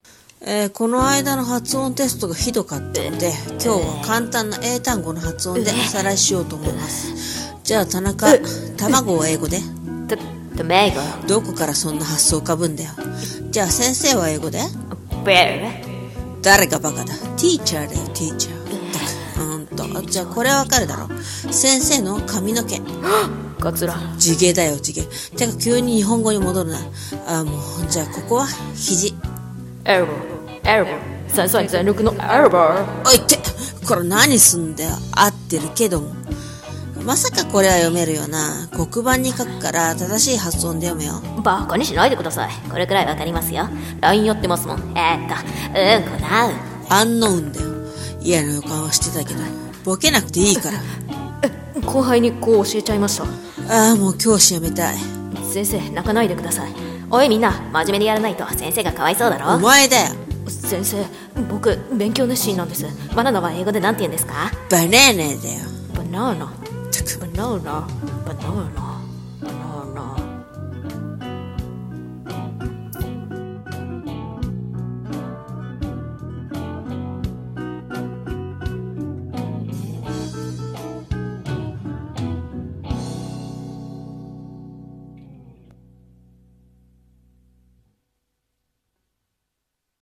【ギャグ声劇】ｴﾙﾎﾞｩ